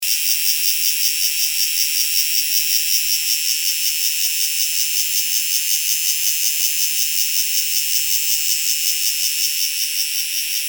2008金山青面姬春蟬.mp3
青面姬春蟬 Euterpnosia viridifrons
新北市 金山區
錄音環境 溪邊雜木林
雄蟬鳴唱